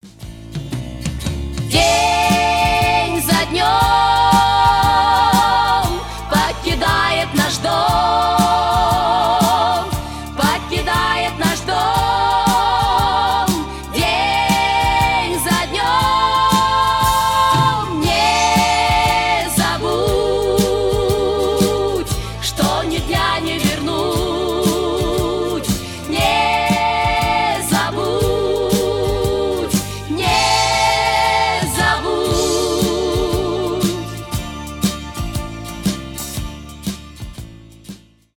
ретро
80-е , поп